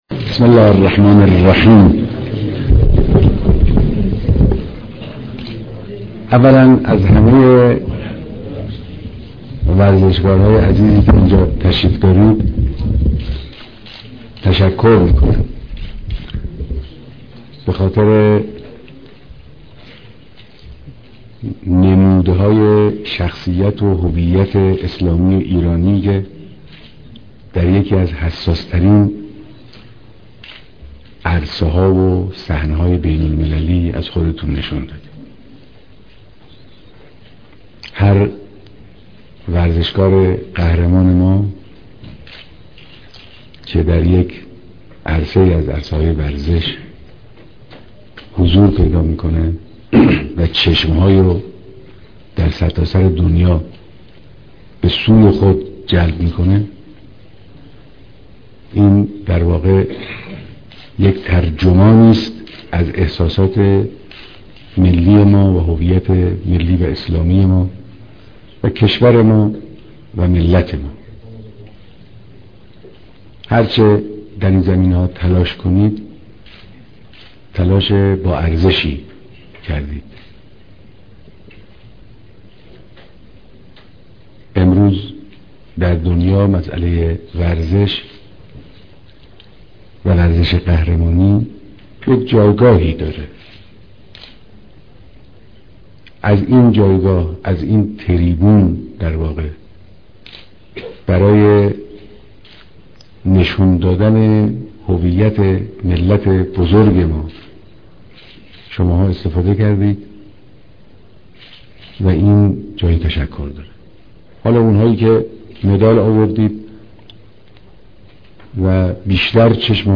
بيانات در ديدار با ورزشكاران شركتكننده در المپيك و پارالمپيك